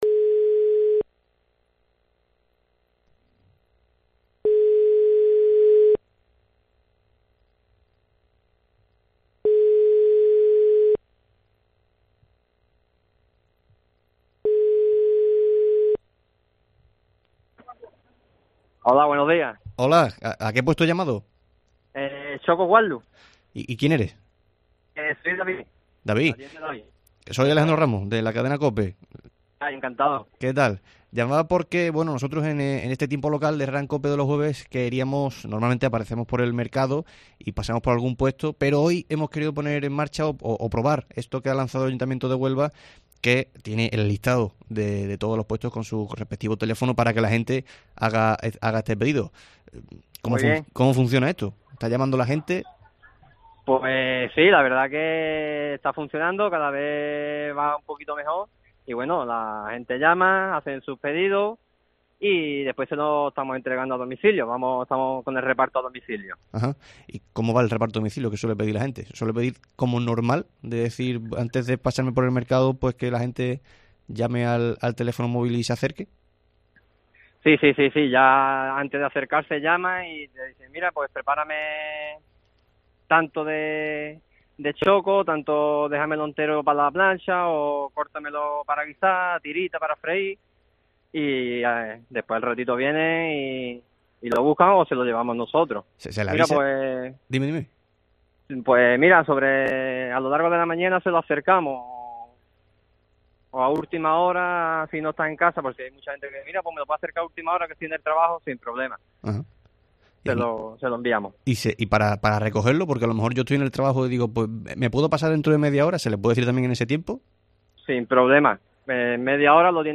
Llamamos al Mercado del Carmen gracias al listado publicado para hacer pedidos